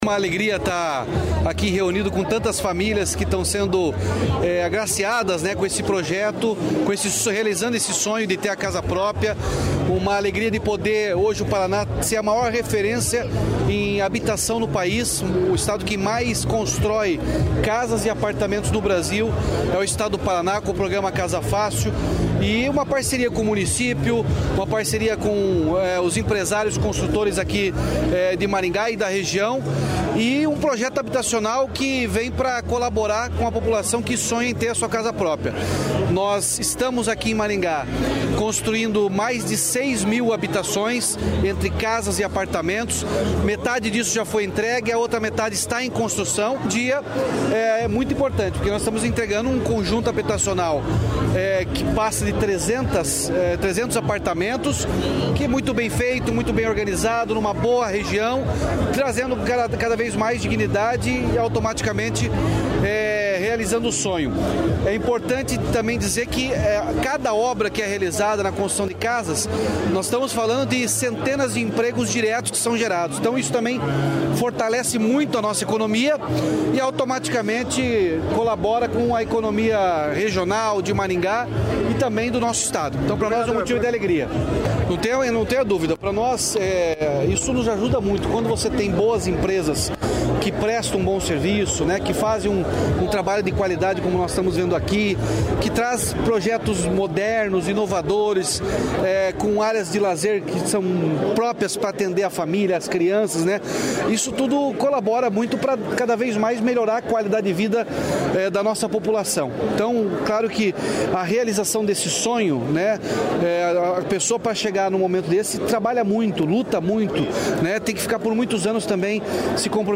Sonora do governador Ratinho Junior sobre o Casa Fácil em Maringá